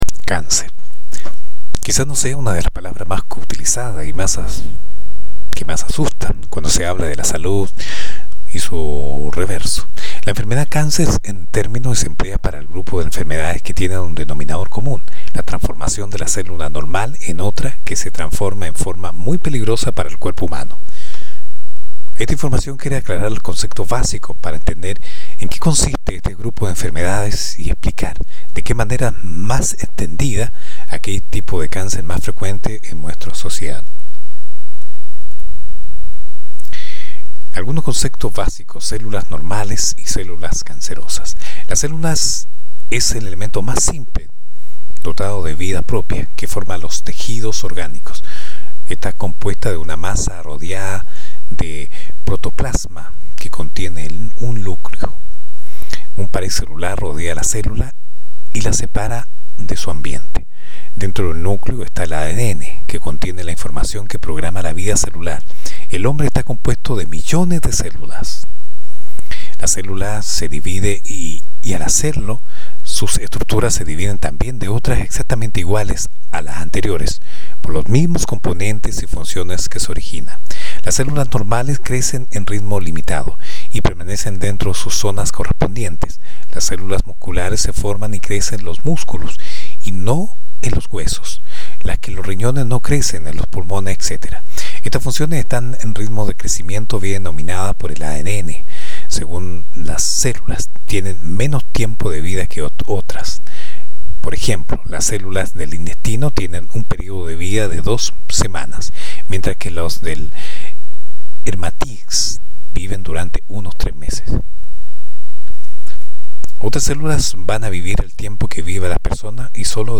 Masculino
Espanhol - América Latina Neutro